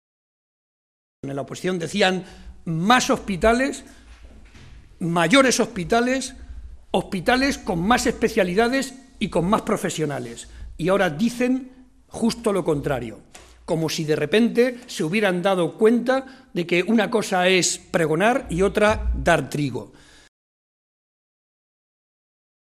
José María Barreda, secretario General del PSOE de Castilla-La Mancha
Cortes de audio de la rueda de prensa